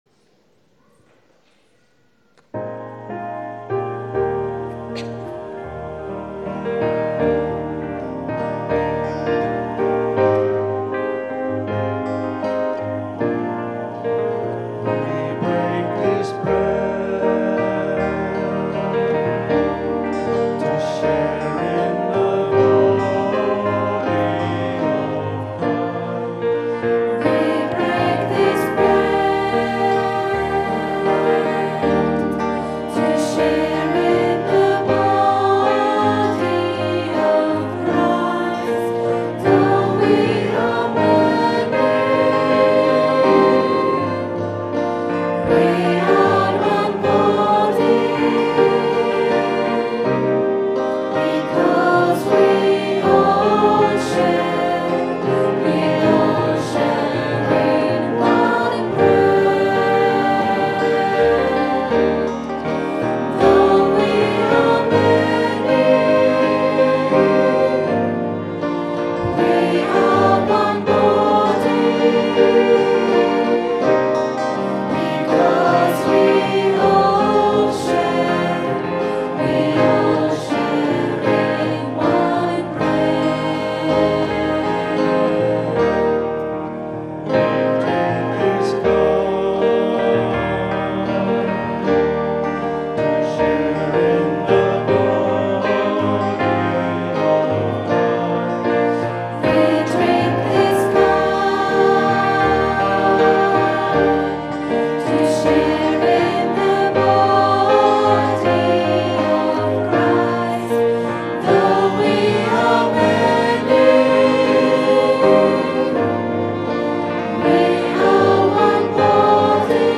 Recorded on a Zoom H4 digital stereo recorder at 10am Mass Sunday 14th November 2010.